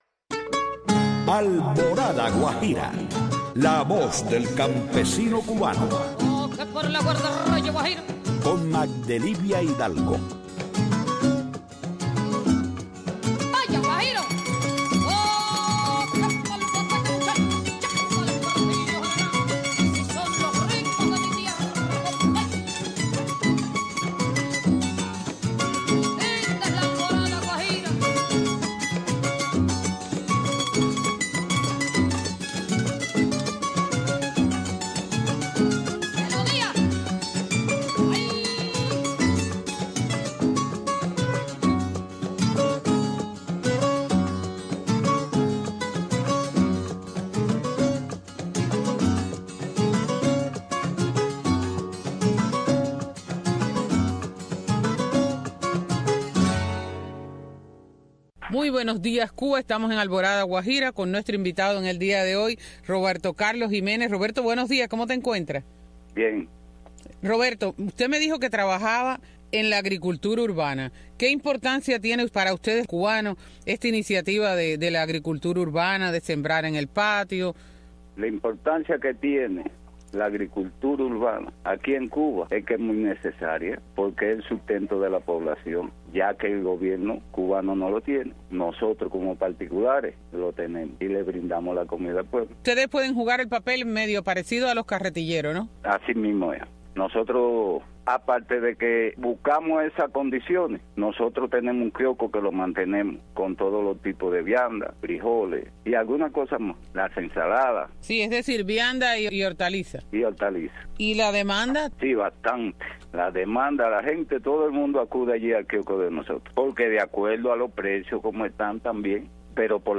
Una hora con temas para el campesino, entrevistas y música.